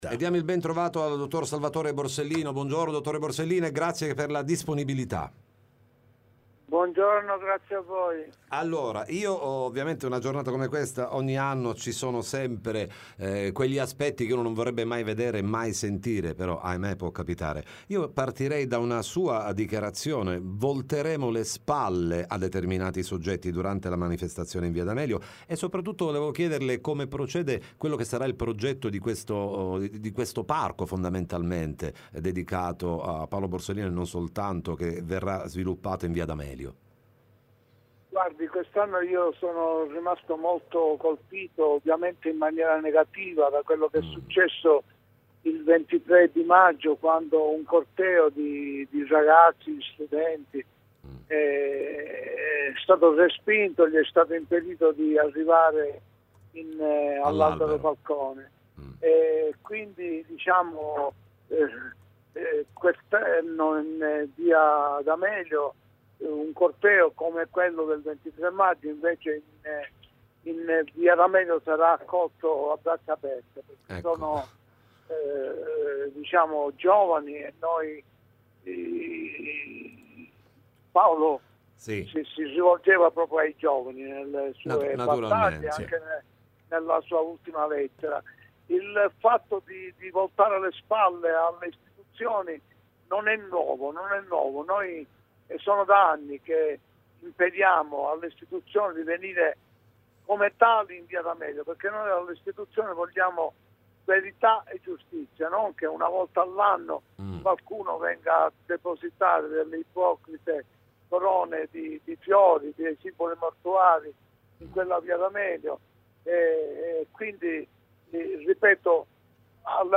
Borsellino, il 31° anniversario della strage Interviste Time Magazine 19/07/2023 12:00:00 AM / Time Magazine Condividi: Borsellino, il 31° anniversario della strage, ne parliamo con il Dott.